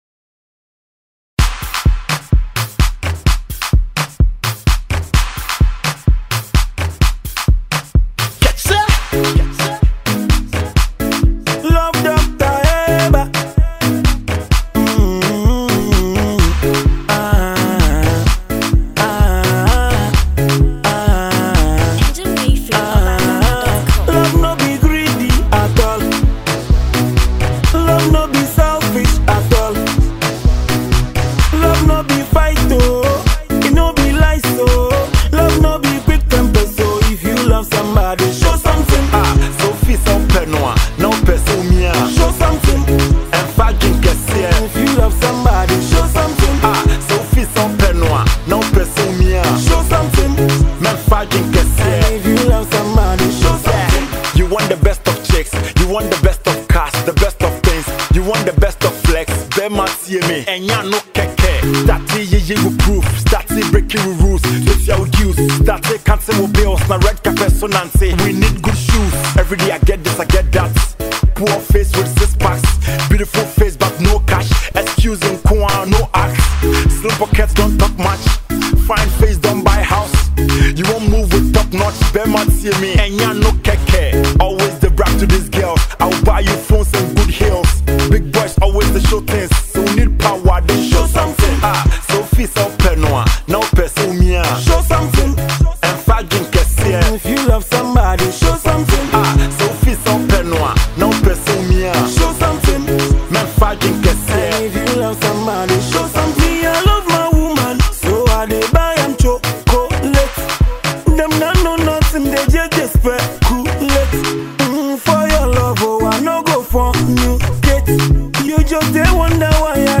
Ghanaian Hiplife Music Duo